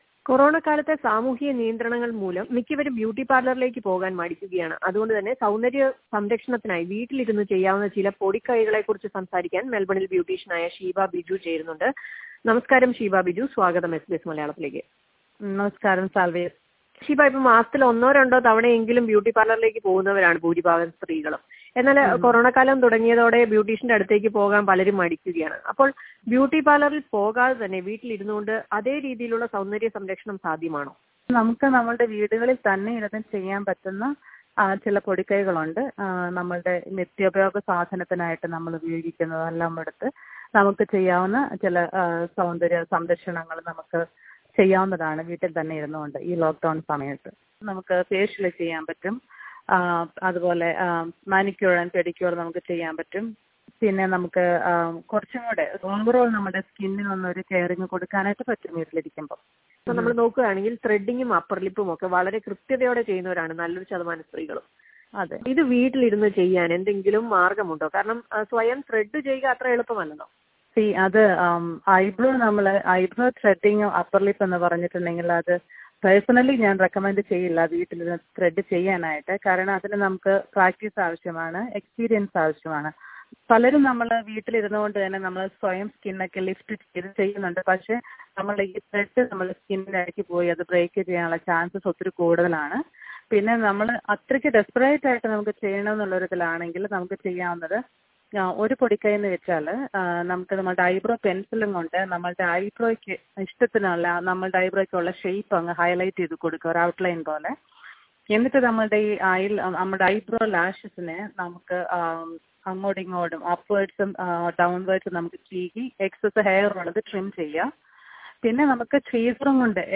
beautician_interview.mp3